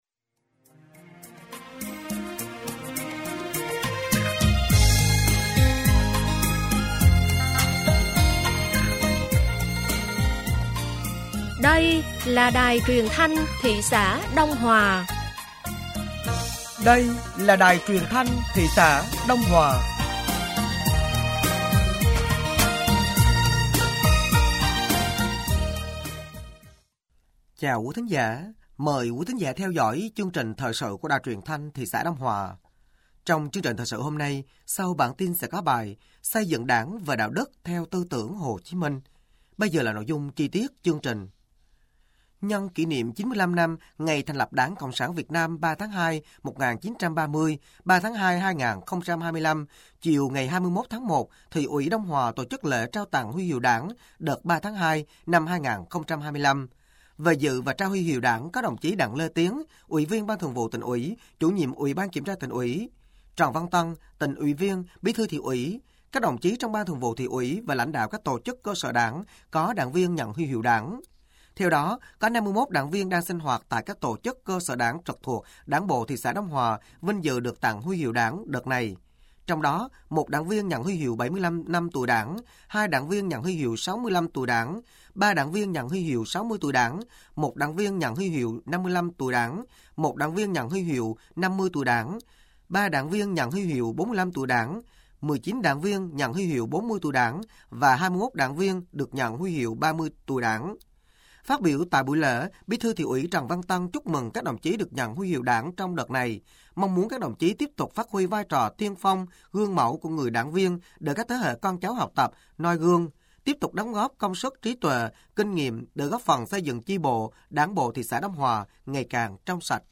Thời sự tối ngày 22 và sáng ngày 23 tháng 01 năm 2024